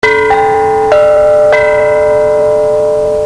Each bell produces three tuned notes: the larger the windbell the deeper the pitches and richer the tones.
It gives you three mid to deep sounds and is even richer yet.